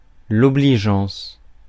Ääntäminen
Ääntäminen France: IPA: [ɔ.bli.ʒɑ̃s] Haettu sana löytyi näillä lähdekielillä: ranska Käännös Ääninäyte Substantiivit 1. kindness US 2. helpfulness 3. obligingness 4. consideration US 5. decency Suku: f .